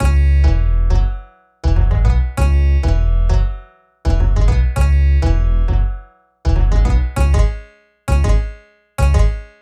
Bass 28.wav